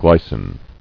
[gly·cin]